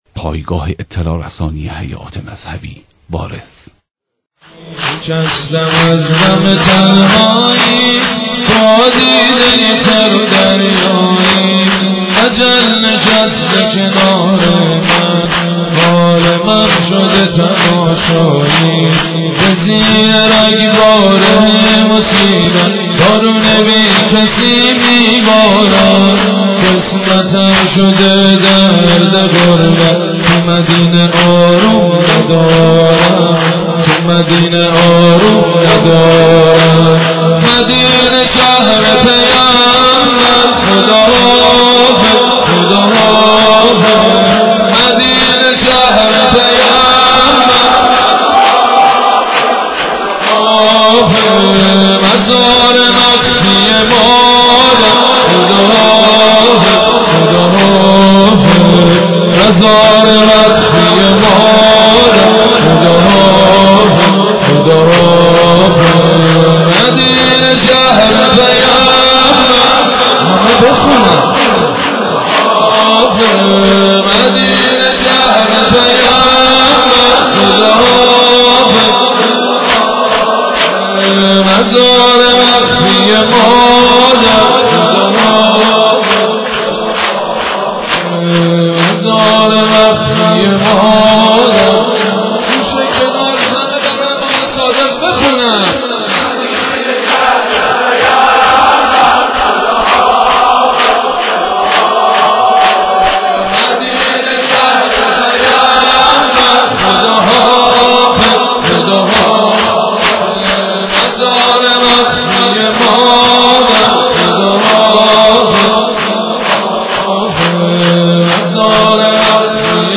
مداحی حاج سید مجید بنی فاطمه به مناسبت شهادت امام صادق (ع)